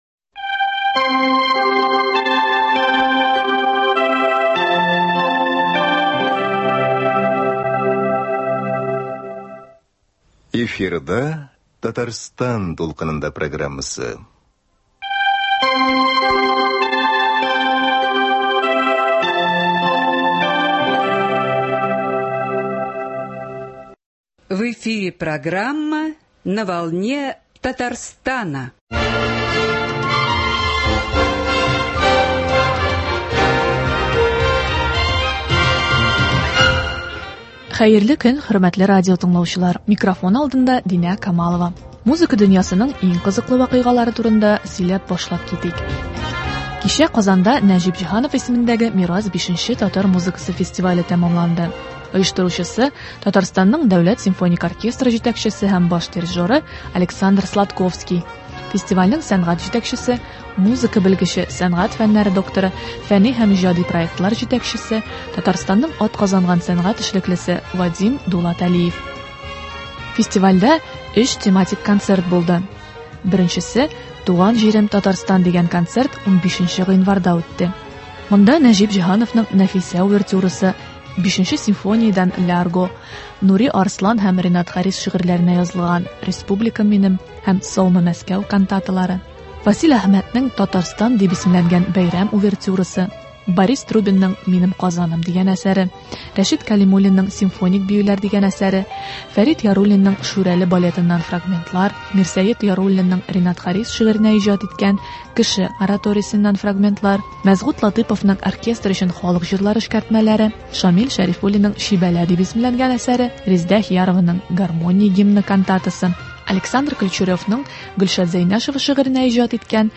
Тапшыруда шушы фестивальдән репортаж тыңлагыз.